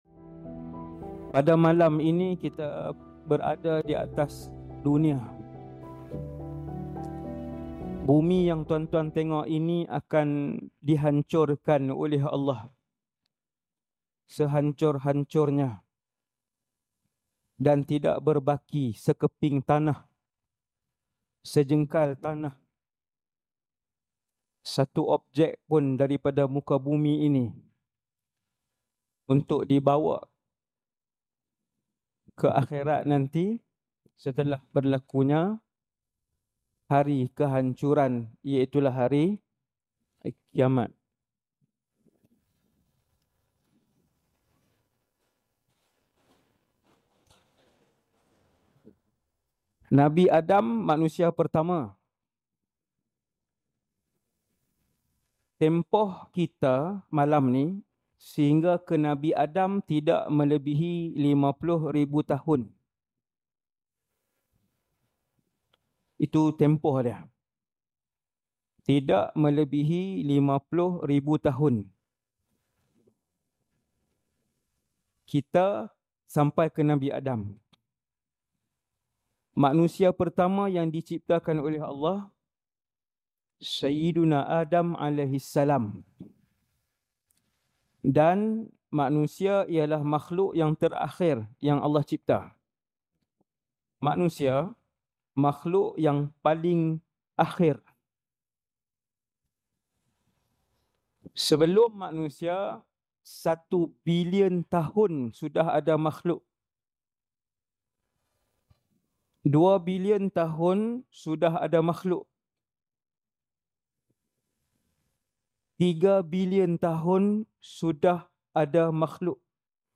Ceramah Penuh - Alam Mahstar_ Peringatan